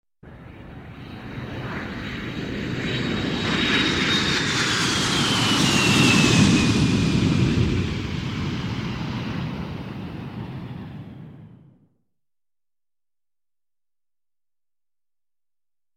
Самолеты звуки скачать, слушать онлайн ✔в хорошем качестве
Пассажирский самолет Боинг-747 пролетает мимо Скачать звук music_note авиа , Самолеты save_as 251.4 Кб schedule 0:16:00 10 1 Теги: Boeing , mp3 , авиа , аэропланы , Боинг , движение , звук , пролет , самолеты , Транспорт